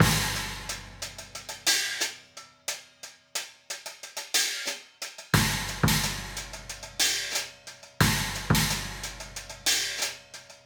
Hi Hat and Kick 03.wav